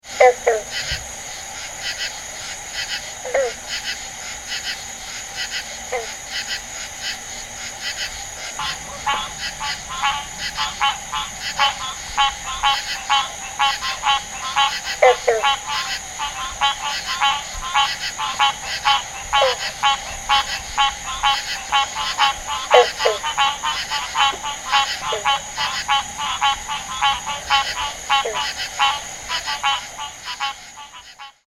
Advertisement Calls
The advertisement of the Green Frog (sometimes called the "banjo frog") is a sharp twang, often repeated several times in succession. this sound is frequently described as sounding like plucking a loose banjo string.
sound  This is a 31 second recording of the advertisement calls of several Green Frogs calling at night from a small pond in in Angelina County, Texas. Insects are heard in the background in the beginning, then the loud honking sounds of Hyla cinerea - Green Treefrog, can be heard.
rclamitans.mp3